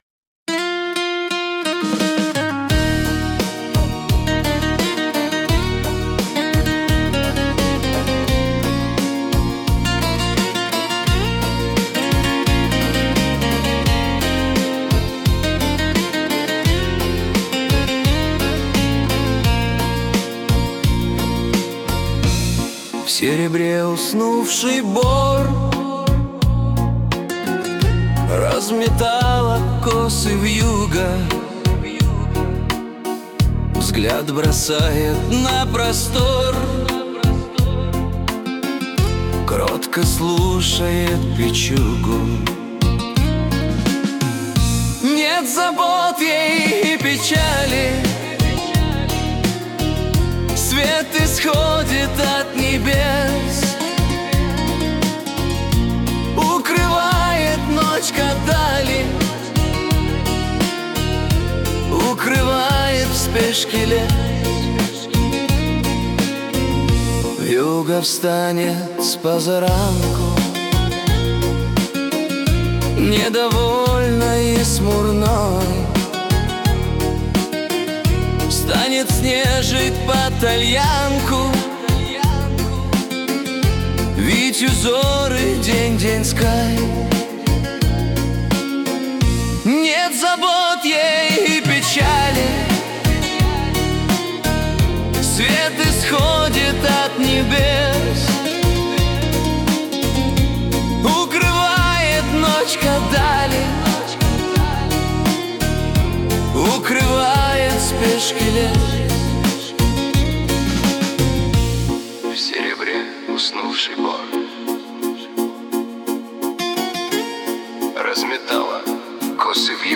Видеопесню В СЕРЕБРЕ УСНУВШИЙ БОР...1с смотреть, слушать, песня сгенерирована автором в нейросети: